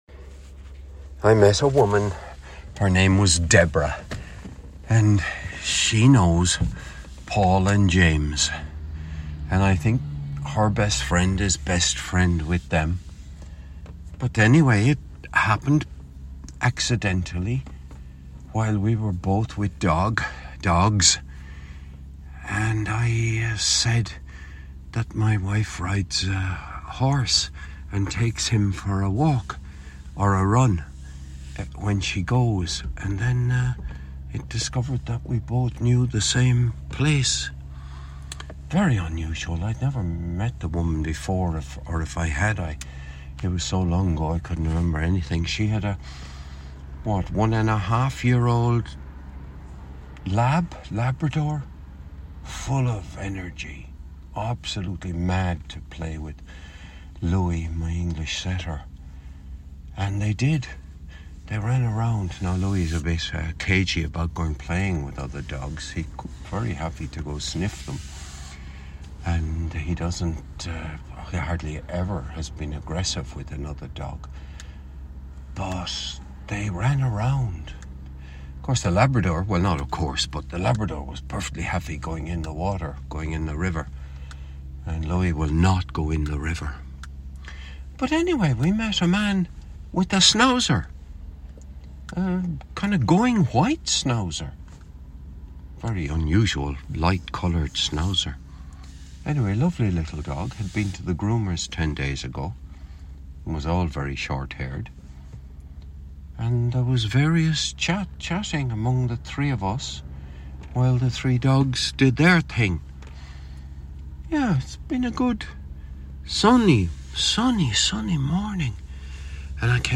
this was recorded on the morning of Thursday 13th of February 2025 - in my kitchen